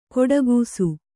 ♪ koḍagūsu